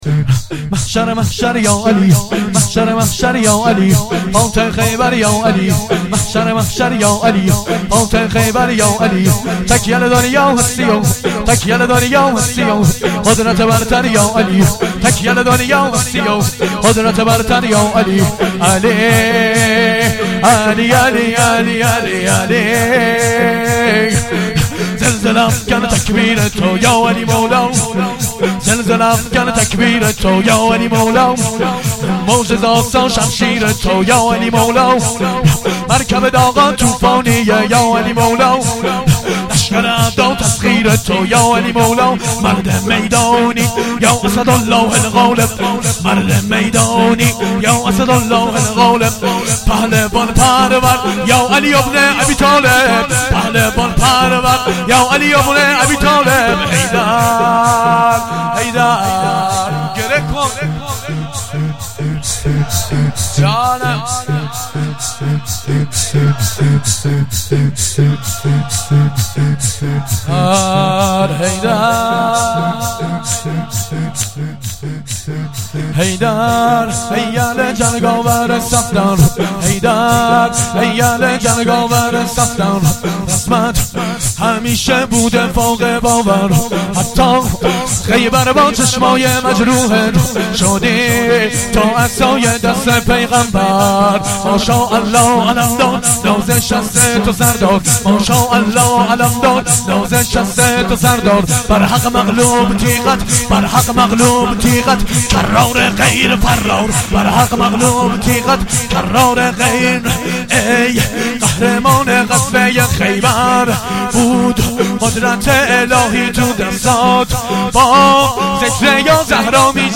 شور مراسم بدرقه زائران اربعین